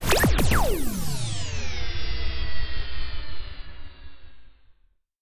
06673 electronic flipper bonus
analog bleep bonus ding effect electronic flipper game sound effect free sound royalty free Sound Effects